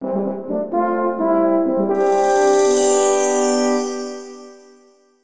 レベルアップ時ジングル。ホルンのハーモニーが心地よい。